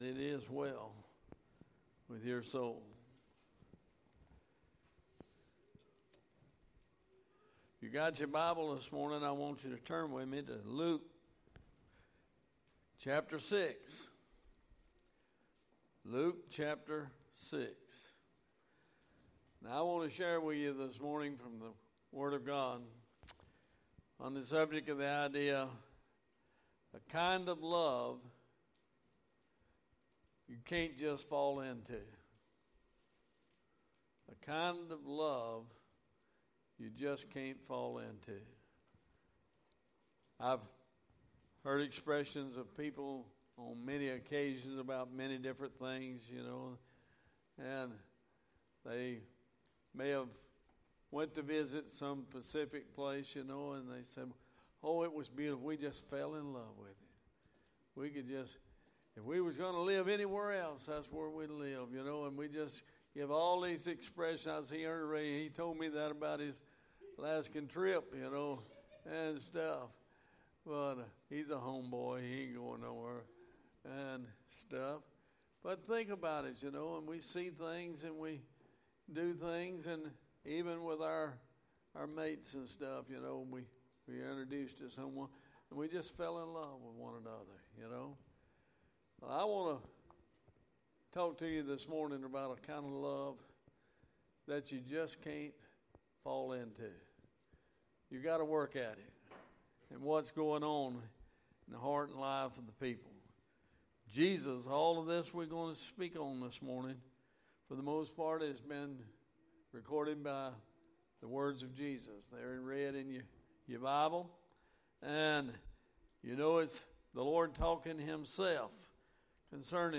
Sermons | Bexley Baptist Church